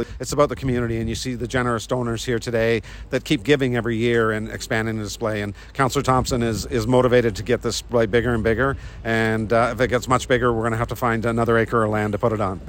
Mayor Neil Ellis thanked the donors who he says have helped make the Festival of Lights one of the largest holiday lighting displays in eastern Ontario.